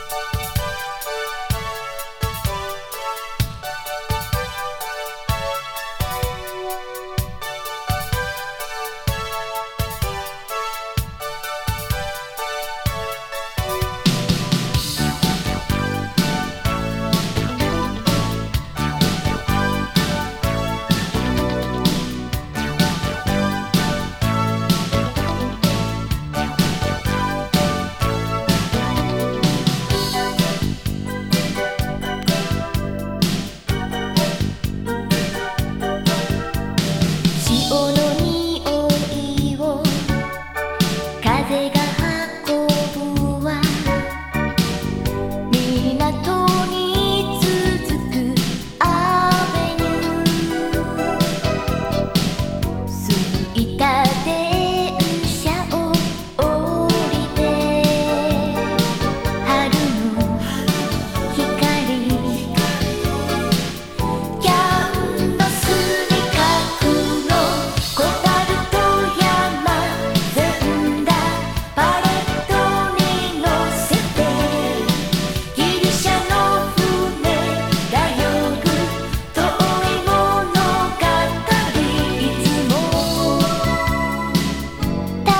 イントロのからワクワクするシンセポップチューン。